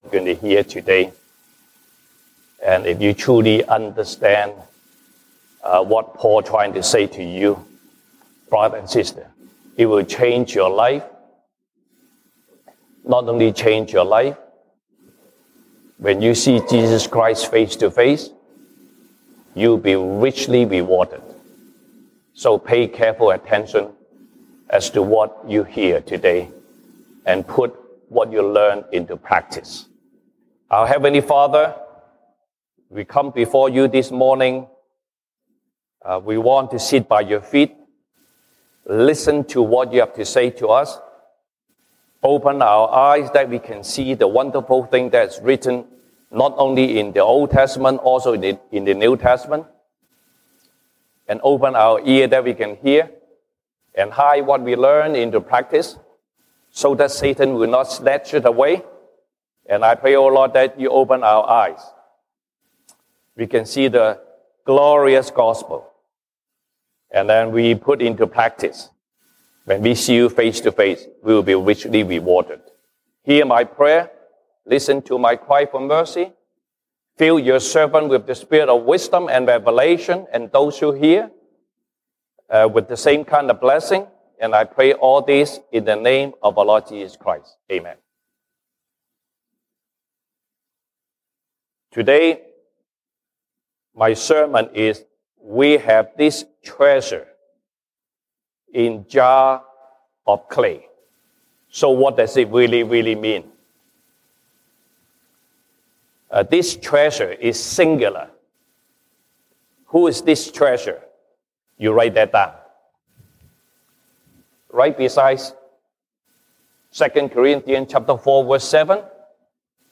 Passage: 歌林多後書 2 Corinthians 4:1-18 Service Type: 西堂證道 (英語) Sunday Service English